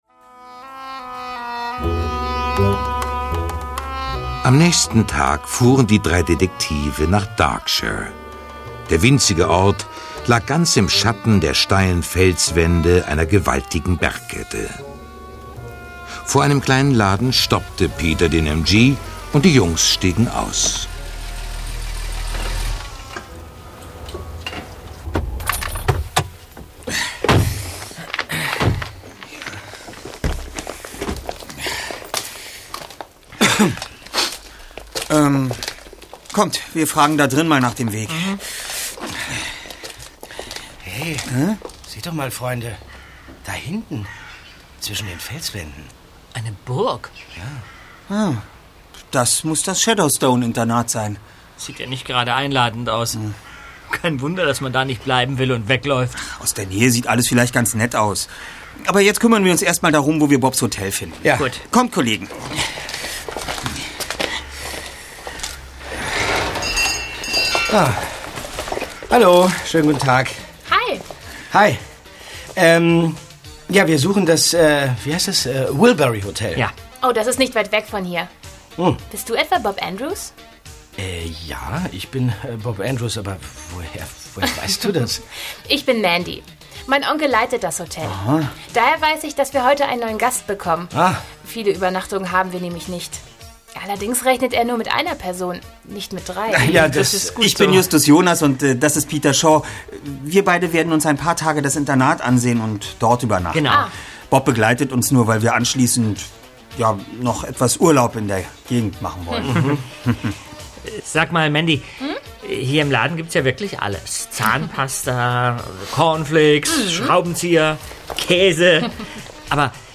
Die drei ??? - Die Rache der Samurai | Physical CD Audio drama
rzähler - Thomas Fritsch